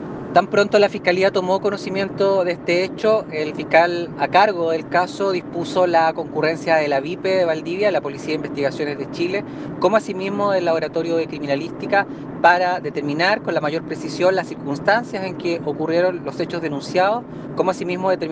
Fiscal Eric Aguayo